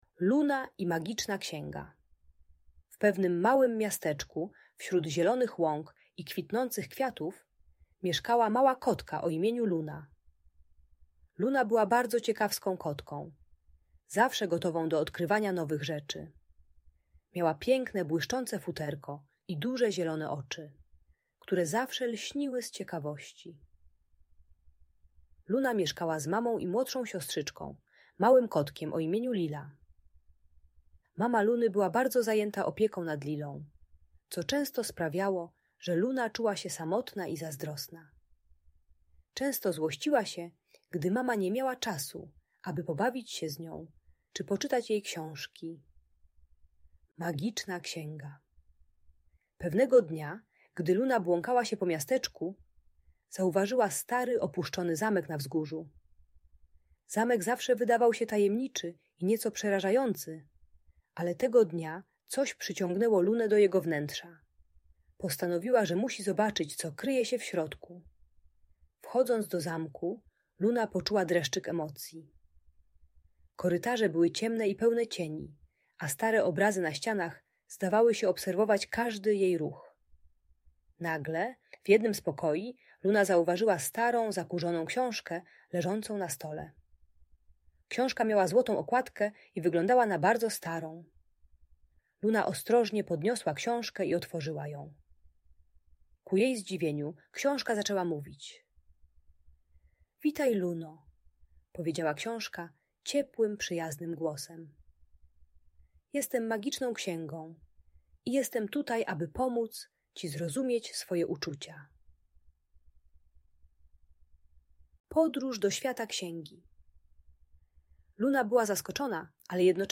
Luna i Magiczna Księga: Urocza historia o empatii i zrozumieniu - Audiobajka dla dzieci